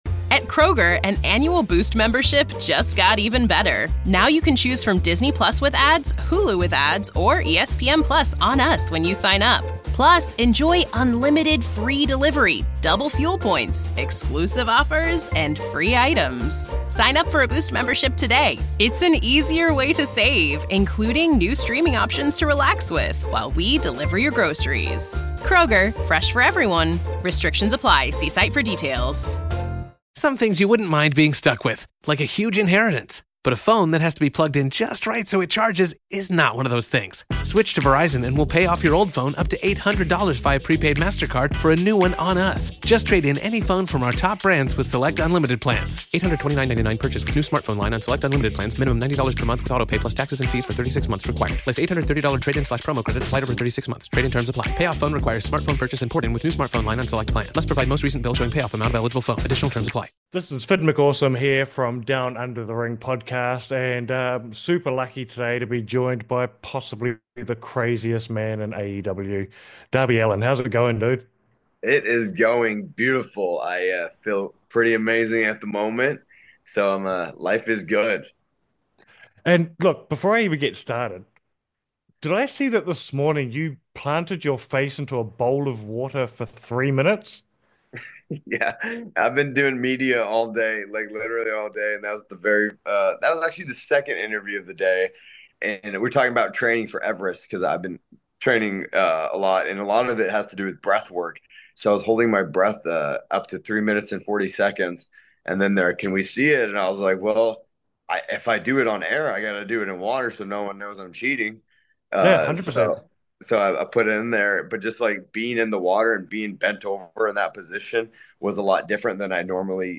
Shoot Interviews Jan 3, 2025 12:45PM Down Under The Ring speaks with Darby Allin ahead of AEW's Collision simulcast debut on Max, and AEW Grand Slam in Australia in February.